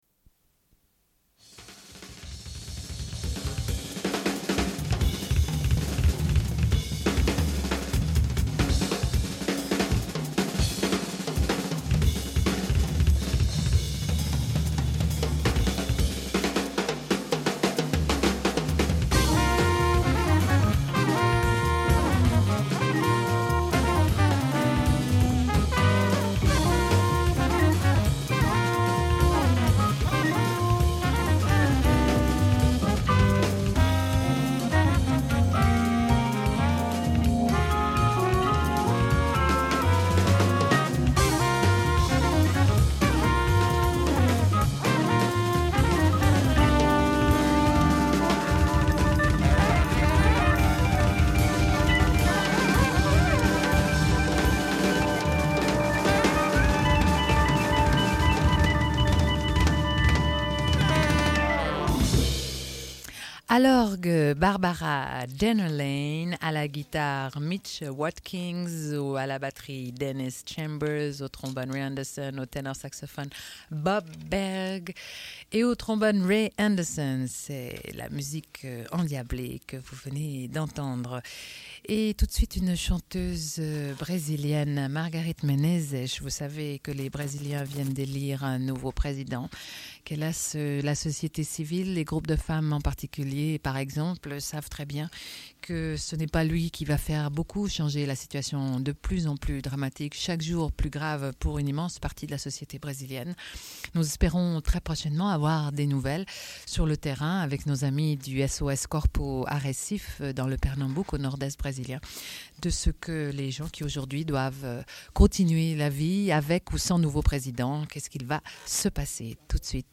Une cassette audio, face A31:07
Sommaire de l'émission : sur la danseur américaine Meg Stuart au sujet de son spectacle « No Longer Ready Made » présenté à la salle Patiño à Genève. Diffusion d'un entretien. Puis sur la poésie populaire de femmes pachtounes en Afghanistan, avec le livre Le suicide et le chant, textes recueillis par Sayd Bahodine Majrouh.